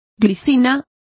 Complete with pronunciation of the translation of wisteria.